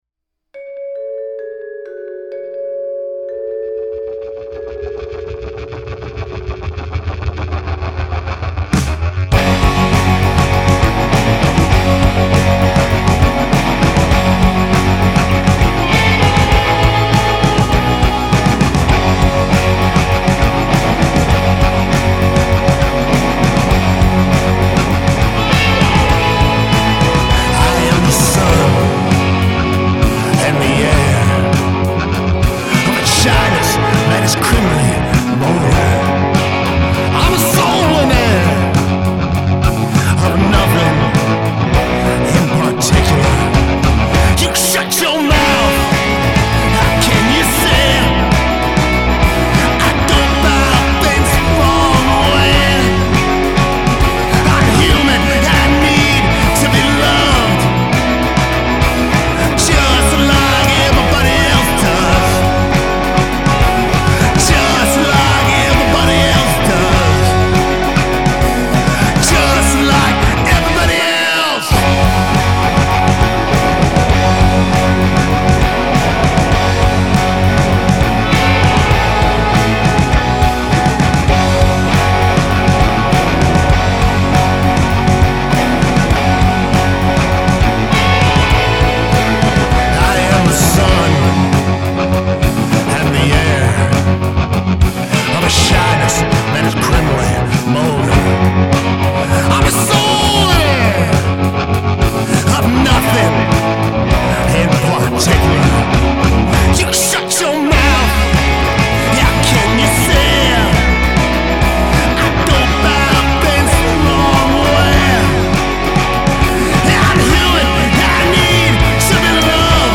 Bass
Drums